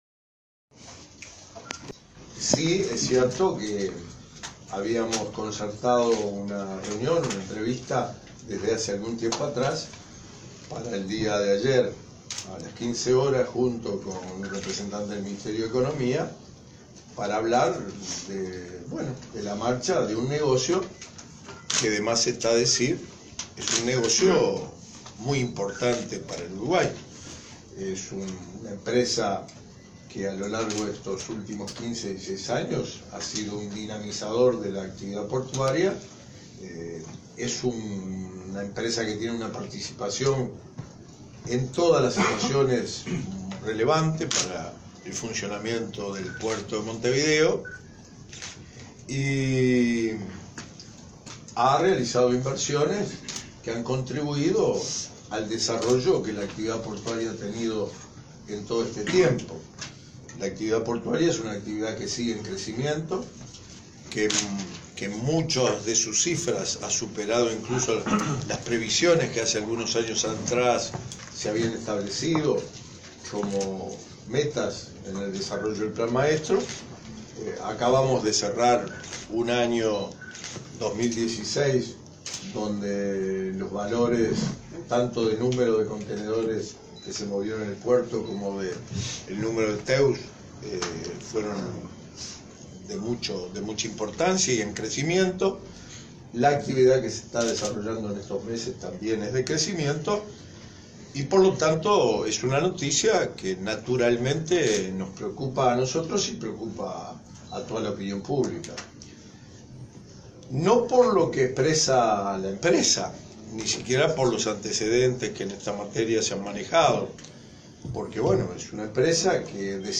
El ministro Víctor Rossi confirmó que si bien la empresa Katoen Natie venderá sus acciones de la terminal de contenedores del puerto de Montevideo, seguirá invirtiendo en Uruguay en otras áreas y no descarta ampliarlas. El jerarca informó a la prensa sobre la reunión que mantuvo esta semana con los directivos de la firma y dijo que hay otros operadores que tienen interés en instalarse en la terminal portuaria.